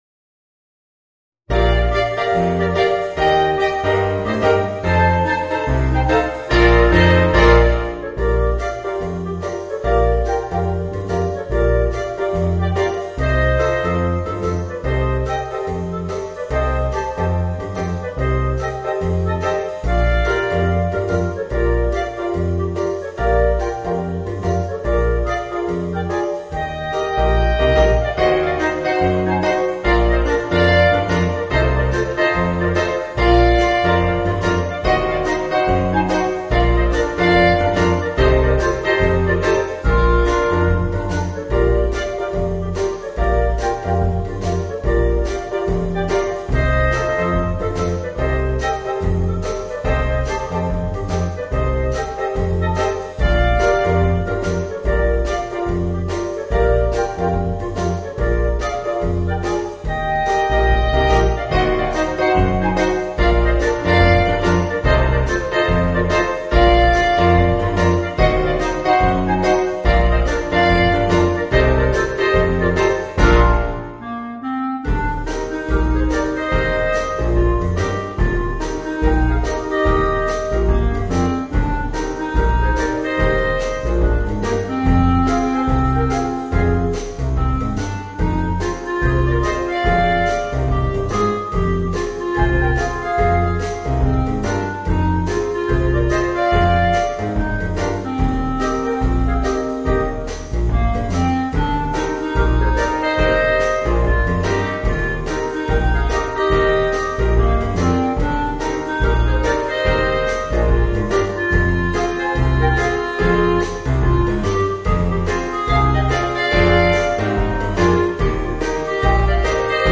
Voicing: 4 Clarinets